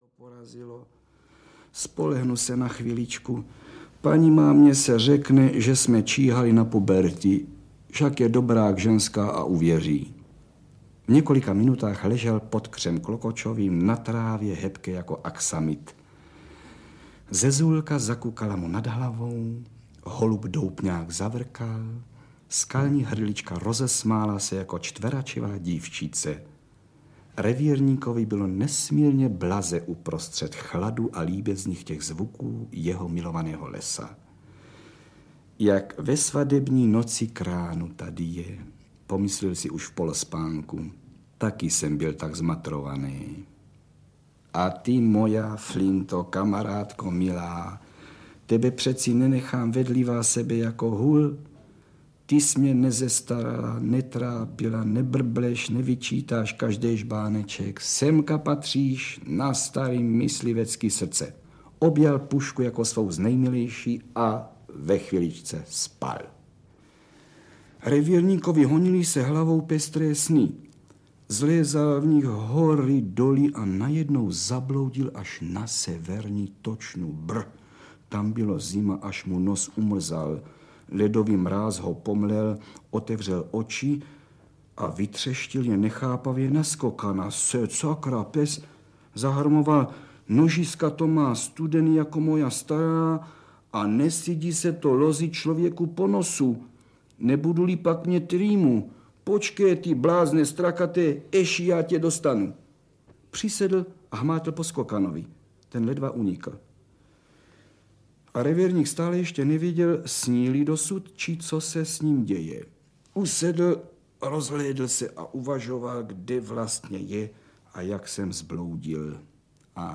Příhody lišky Bystroušky - audiokniha, kterou napsal Rudolf Těsnohlídek a čte Karel Höger. Hudba - Česká filharmonie pod vedením Františka Jílka.